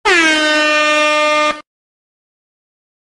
Clip meme de 3 segundos — gratis, en el navegador, sin registro ni descarga obligatoria.
Air horn 2
air-horn-2.mp3